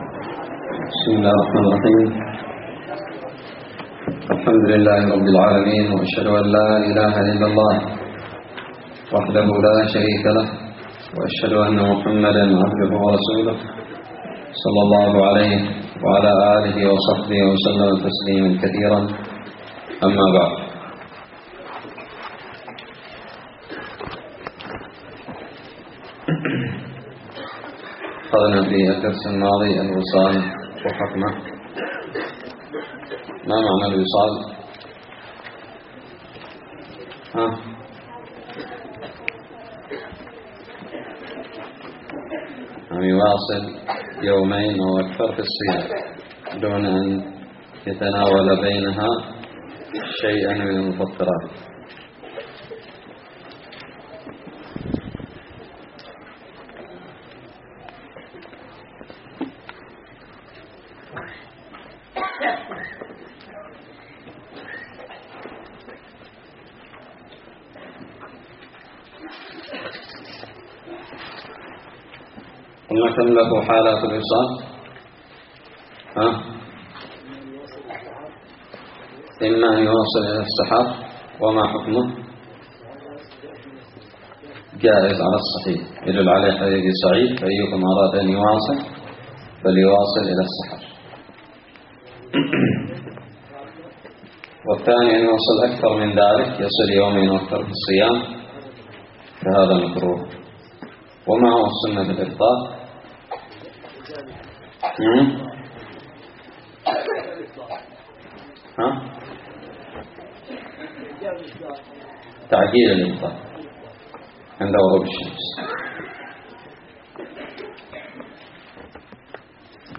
الدرس العشرون من كتاب الصيام من الدراري
ألقيت بدار الحديث السلفية للعلوم الشرعية بالضالع